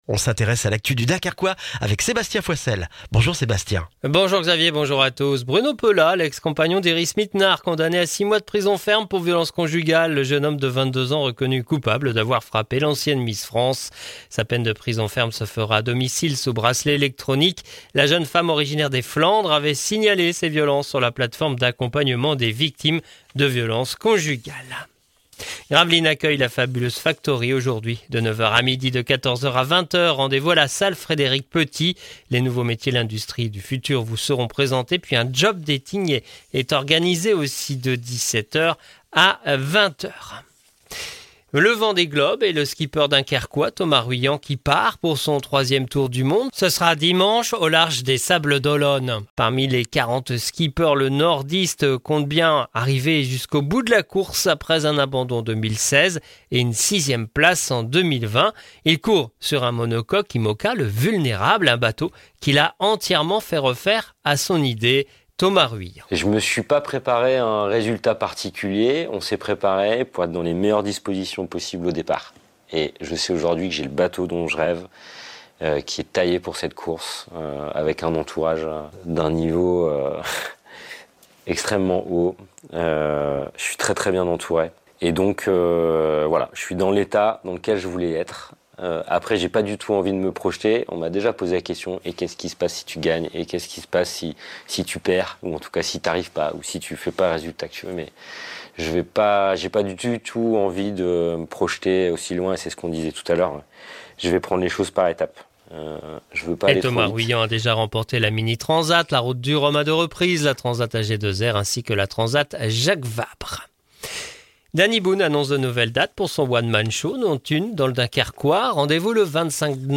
Le journal du vendredi 8 novembre dans le Dunkerquois